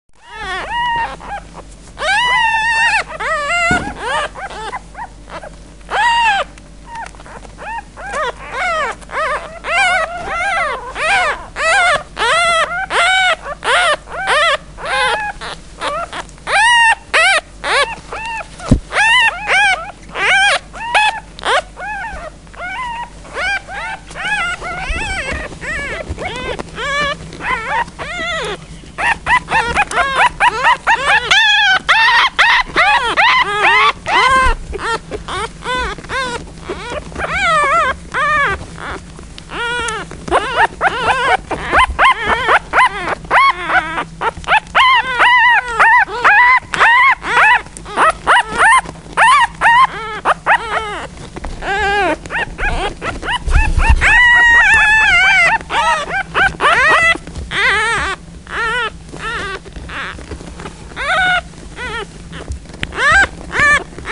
Oggi non riesco ad aggiungere altro; ma darò voce ai quattro fratellini e vi invito ad ascoltare quello che vogliono dirci, prima, durante e dopo il pasto (con il biberon).
1-Pianto prima del pasto
wma volpi.wma